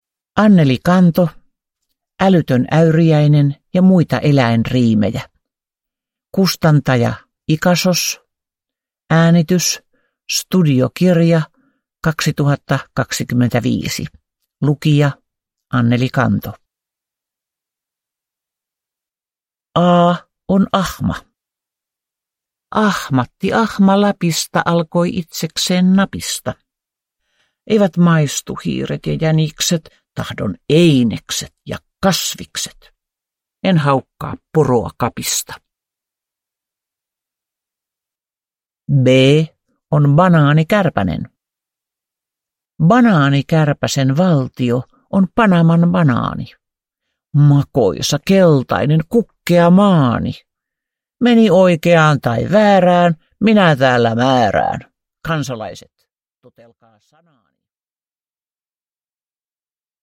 Älytön äyriäinen ja muita eläinriimejä – Ljudbok